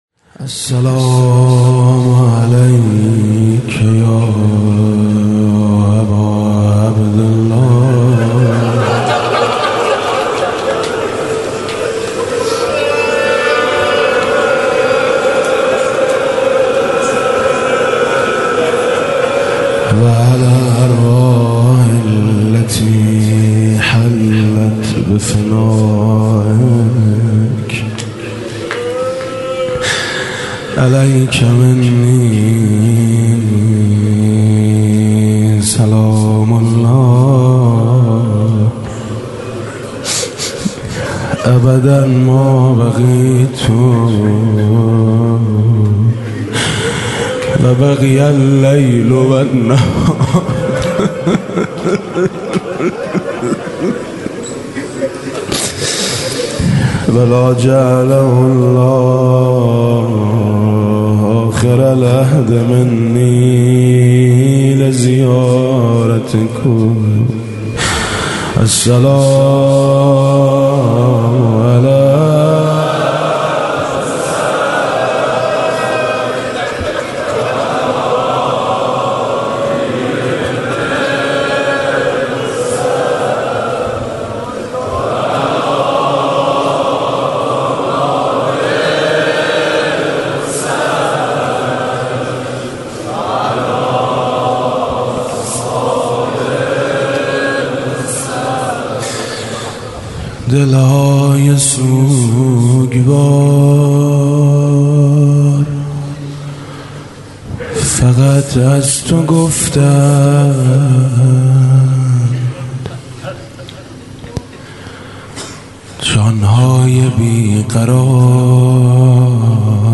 روضه شب سوم محرم95.mp3
روضه-شب-سوم-محرم95.mp3